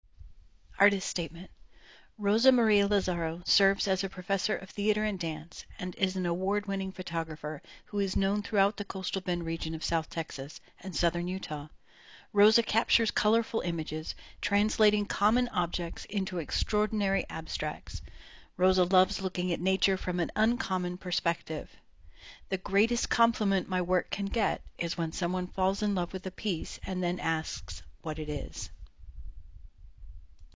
Audio recording of the artist statement